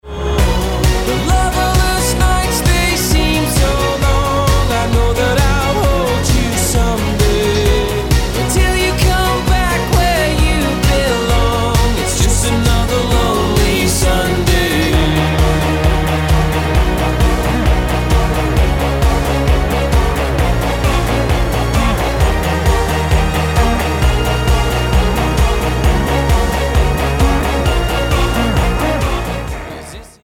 Britská sythpopová skupina